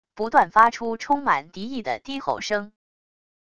不断发出充满敌意的低吼声wav音频